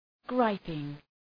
Shkrimi fonetik {‘graıpıŋ}